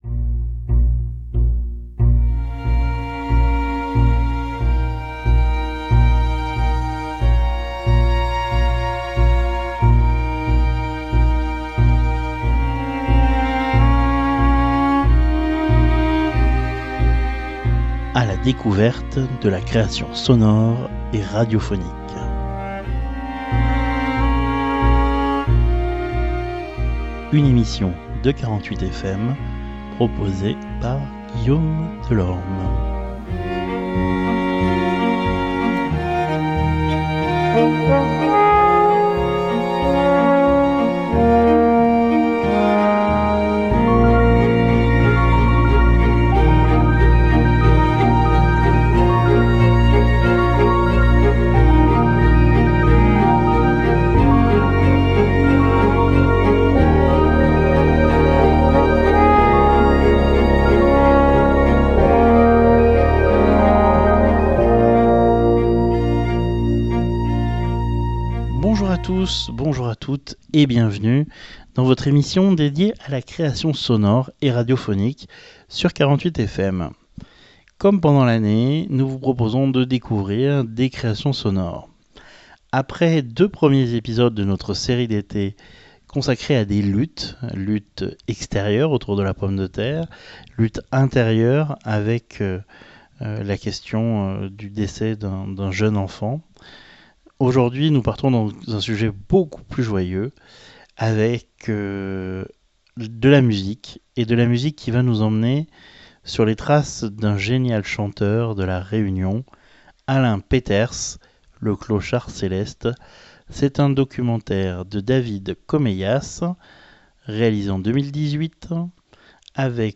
Un documentaire